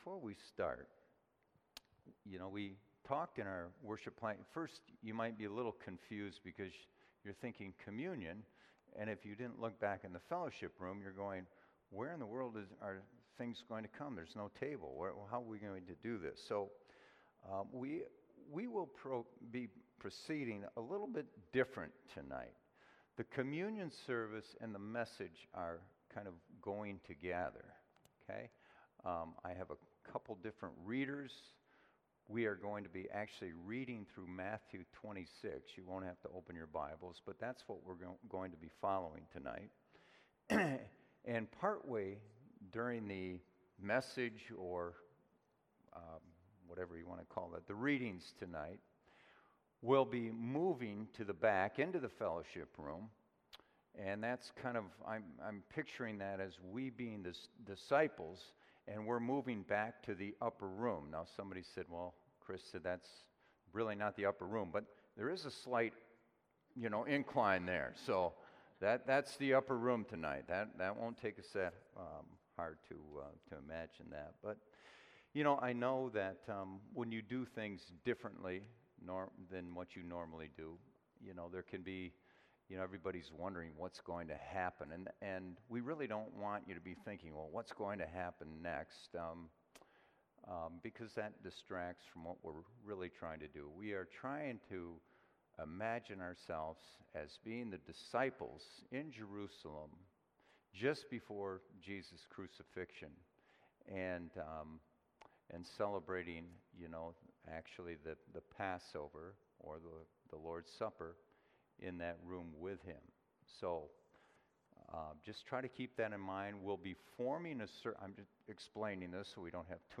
SERMON OUTLINE: “SONGS IN THE NIGHT” Matthew 26:17-30 Afraid of the dark Living in the dark Singing in the dark